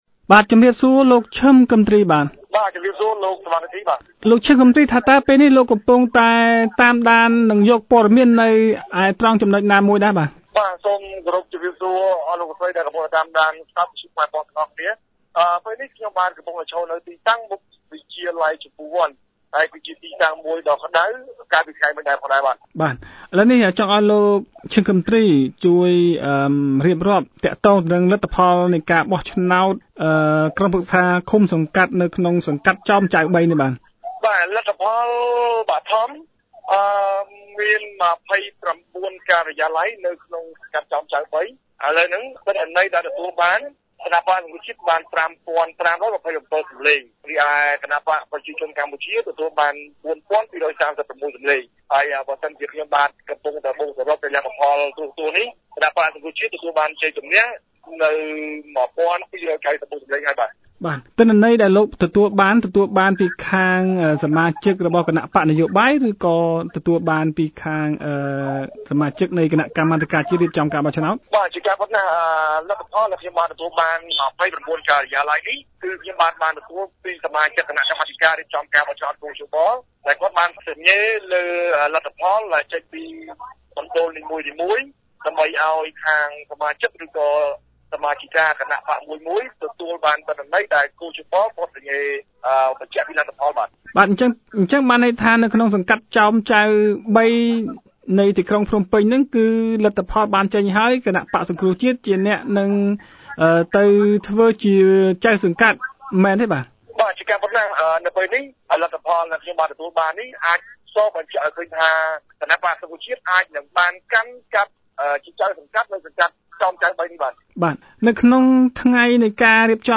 radio report